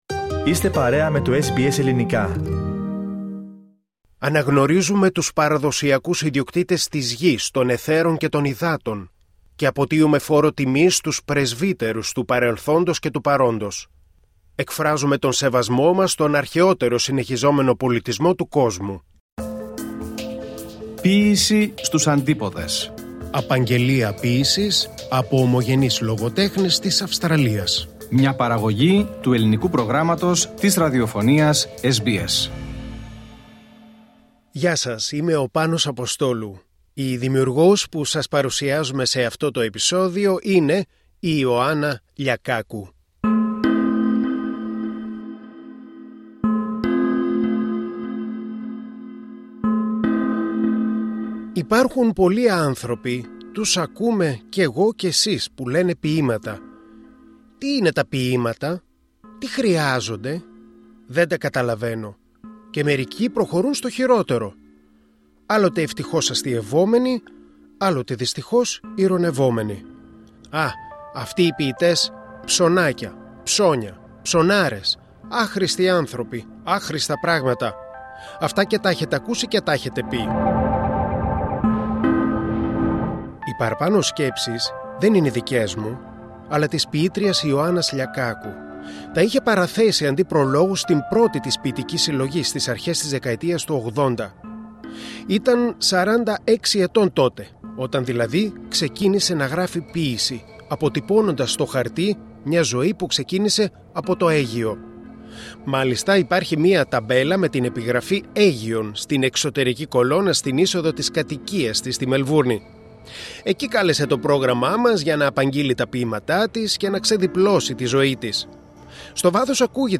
Εκεί κάλεσε το SBS Greek για να απαγγείλλει τα ποιήματά της και να ξεδιπλώσει την ζωή της.